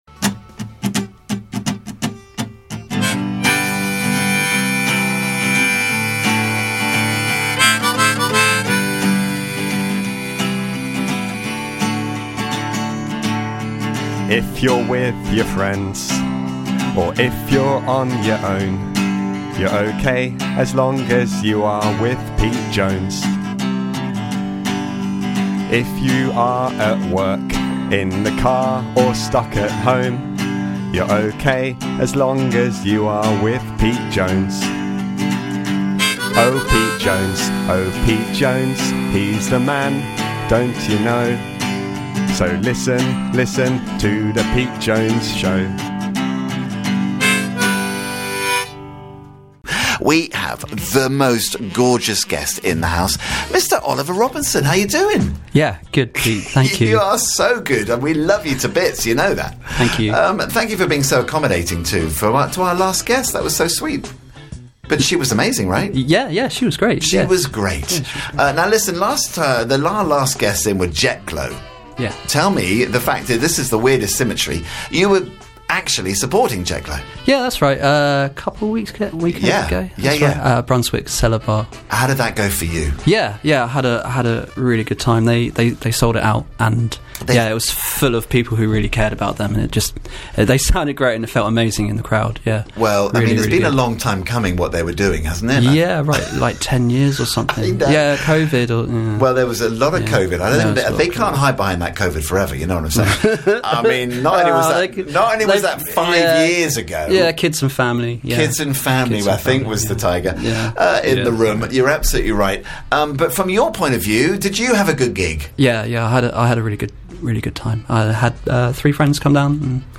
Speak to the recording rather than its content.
in the Reverb Live Lounge 3 live tracks played out for us too: